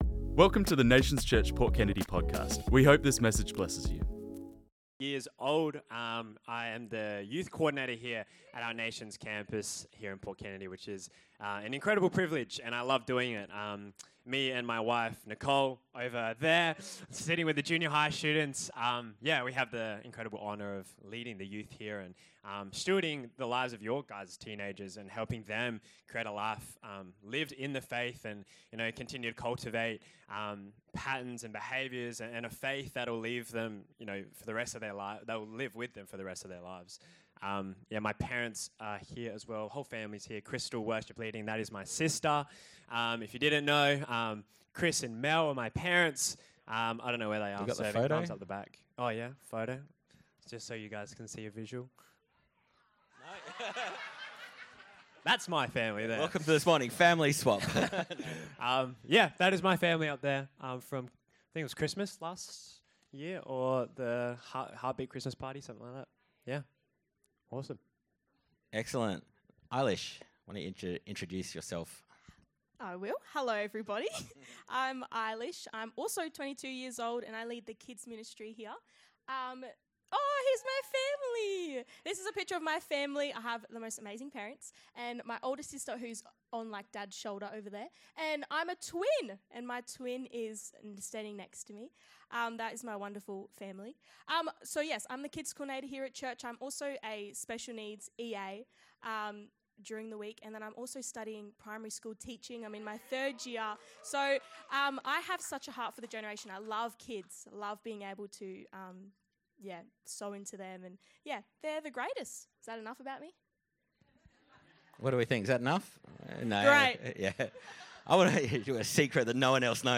Next Gen Sunday Panel